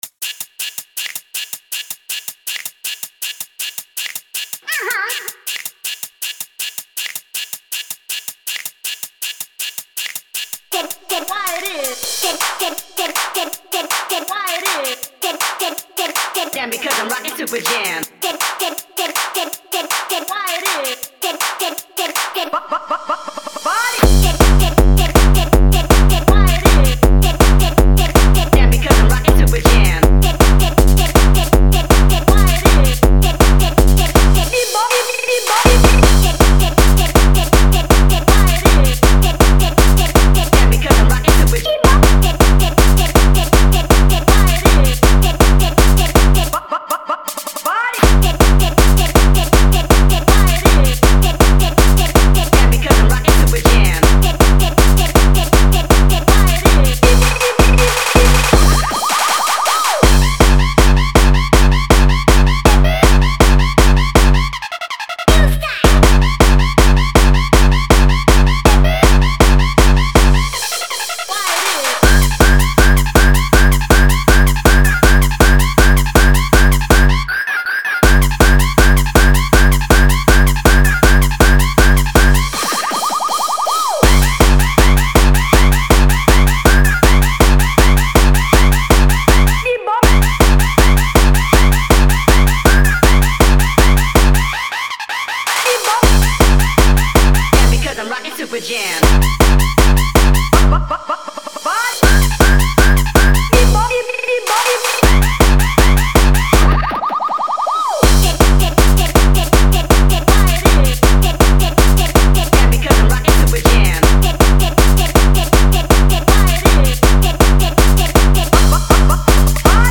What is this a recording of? Género: Newstyle.